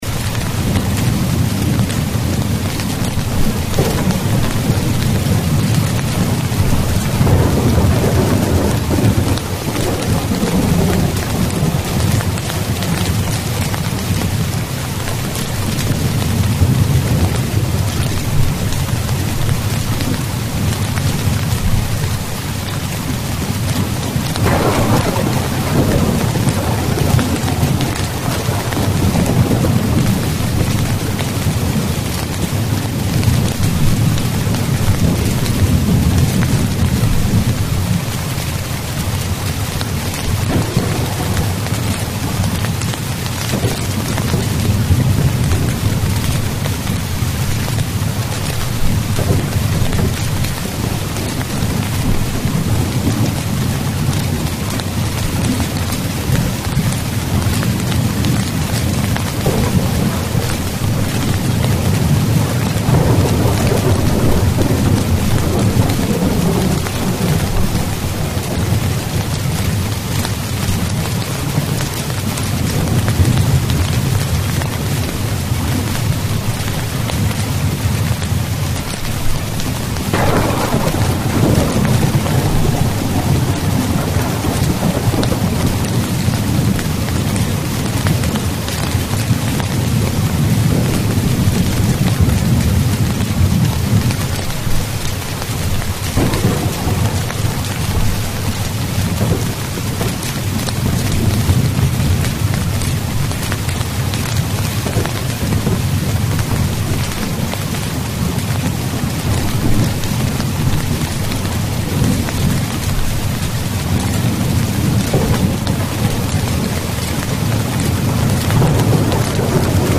Звук дождевых капель стучащих по зонту